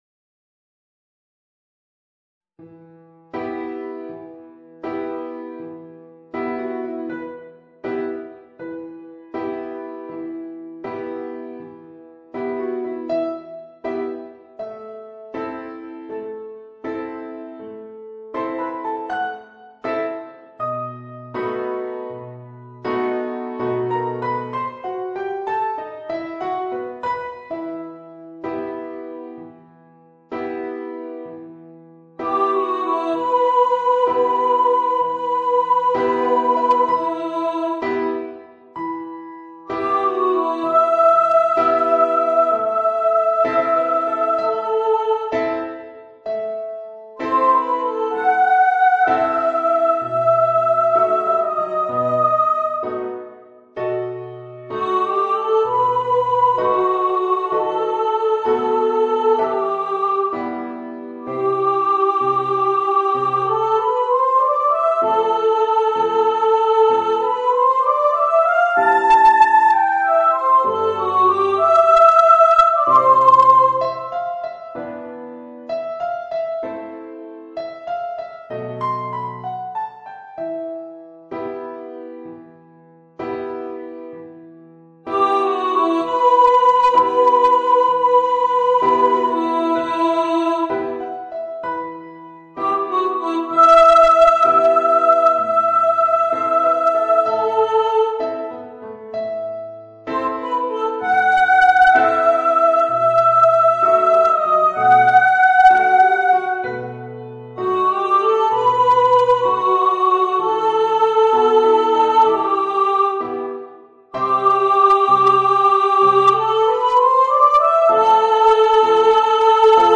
Voicing: Voice and Piano